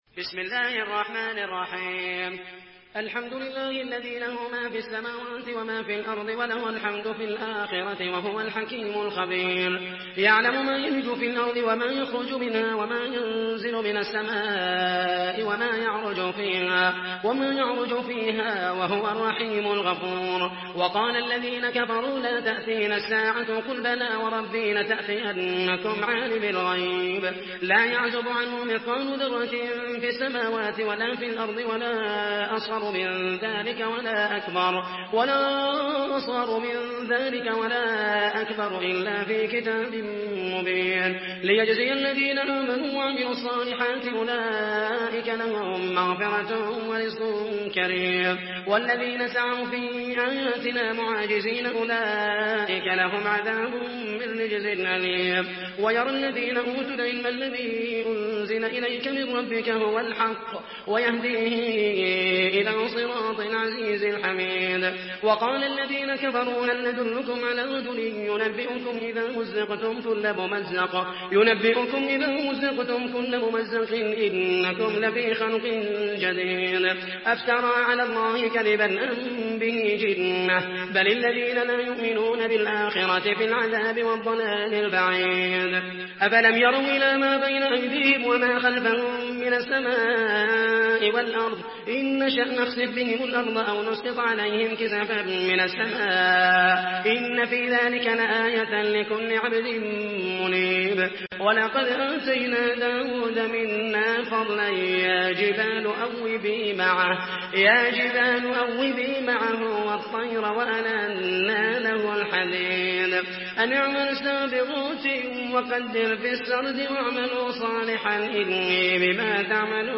Surah Saba MP3 by Muhammed al Mohaisany in Hafs An Asim narration.
Murattal Hafs An Asim